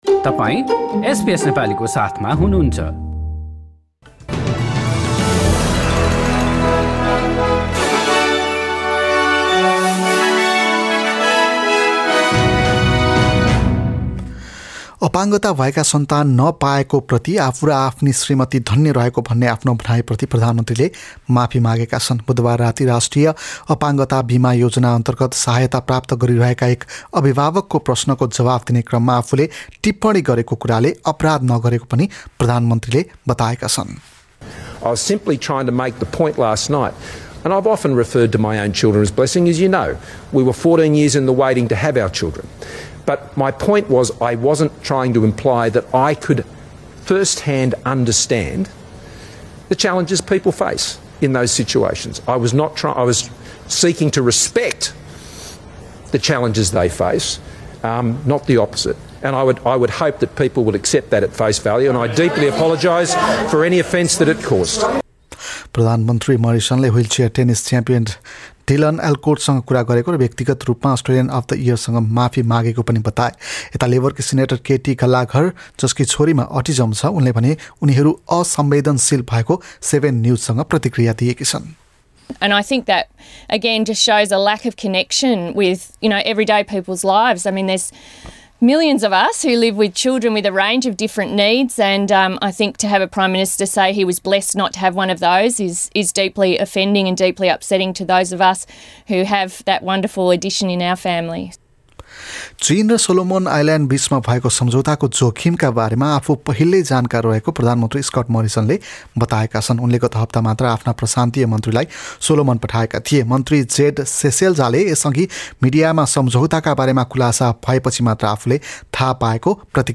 एसबीएस नेपाली अस्ट्रेलिया समाचार: बिहिबार २१ अप्रिल २०२२